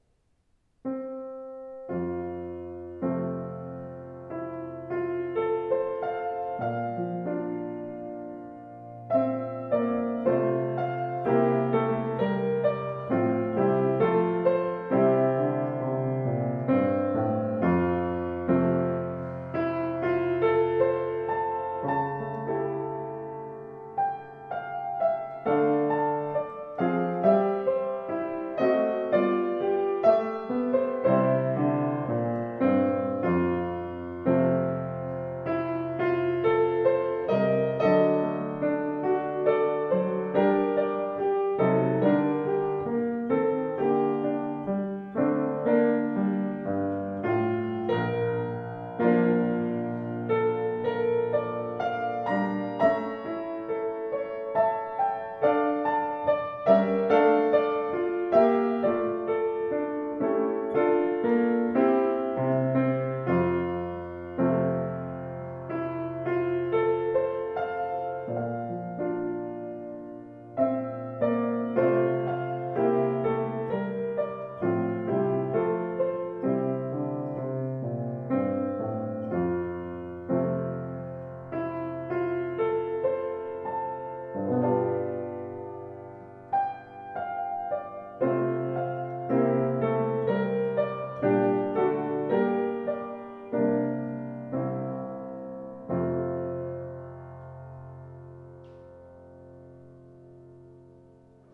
Klaviere